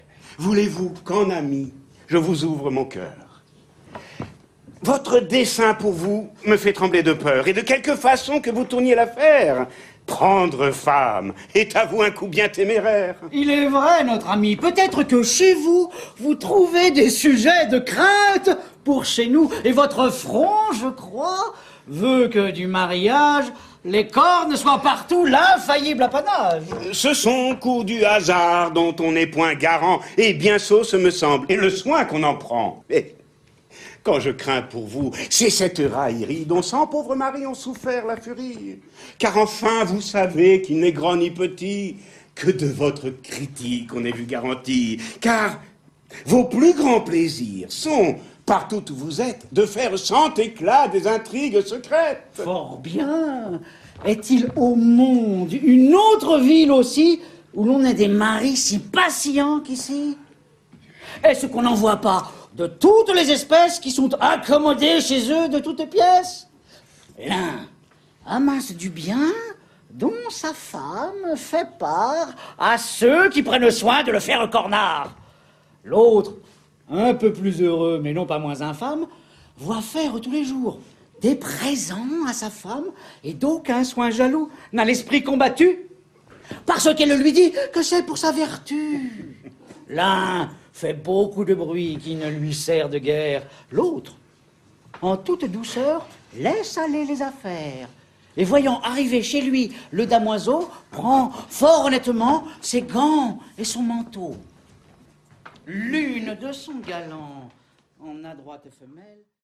On ne dit plus Molière : on le scande !
Le premier par un «scandeur», le deuxième par l'inimitable Louis Jouvet.
Un comédien sans nom :